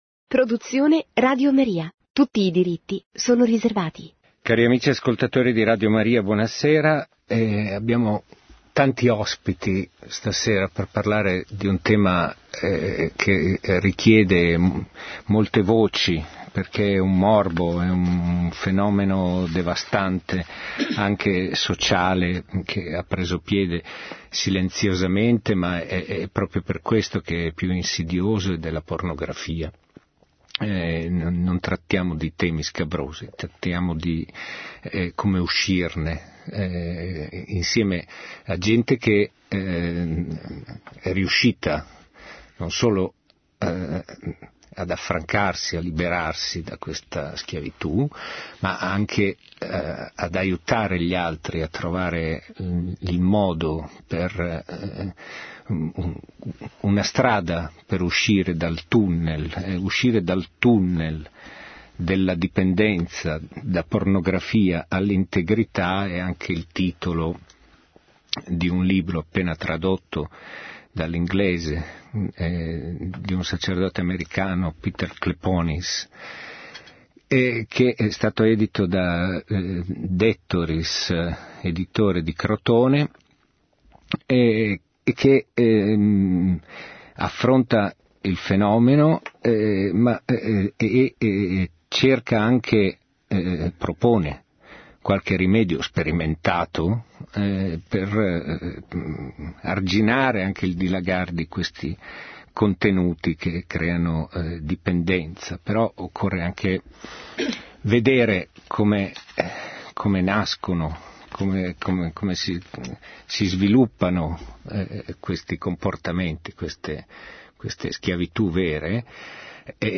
Radio Maria – “Uscire dalla pornografia” – 27 ottobre 2019 – TAVOLA ROTONDA con dott.